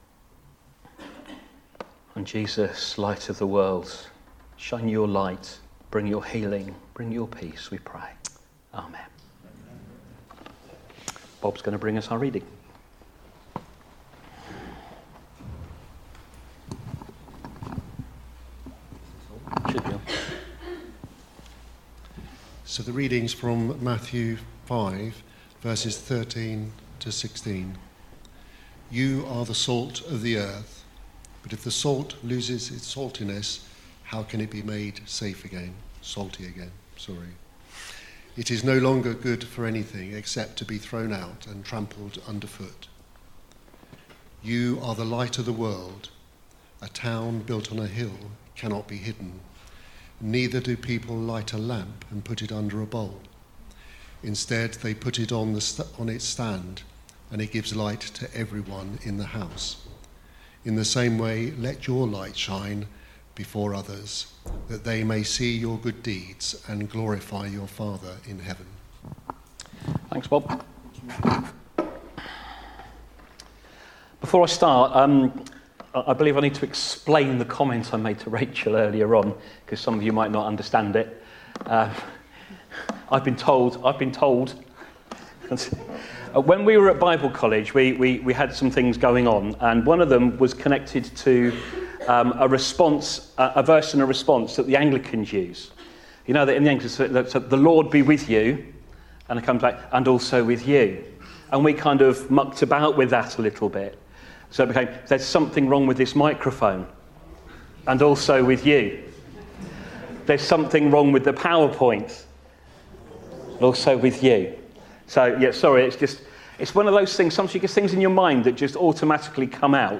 Listen to last week’s sermon for a timely challenge about the events around us on 31st October, and also book your child into our Light Party.